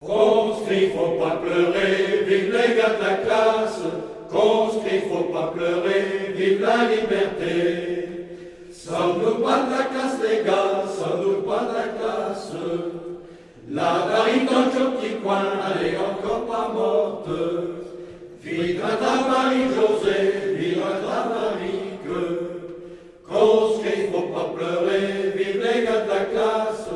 gestuel : à marcher
Groupe vocal
Pièce musicale éditée